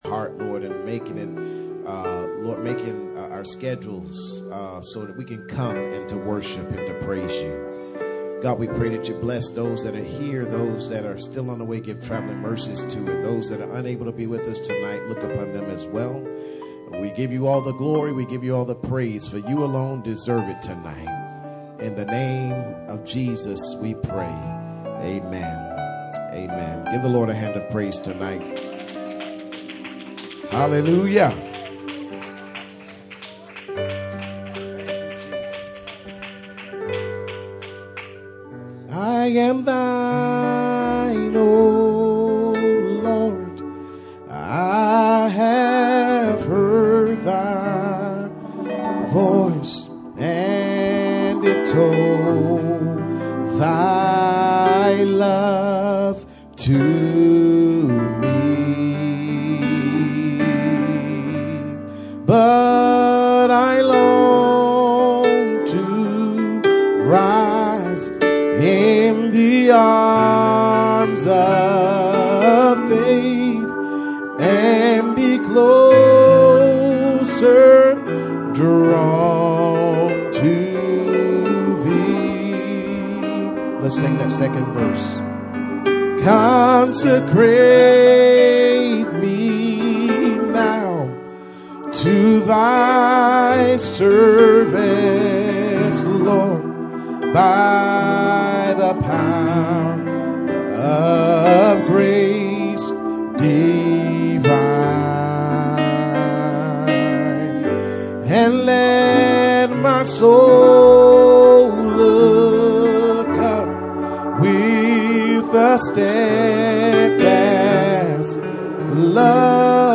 Pastor Passage: 1 Samuel 17:34-47 Service Type: Friday Night %todo_render% « Study on II Samuel 17 Christ In The Life of David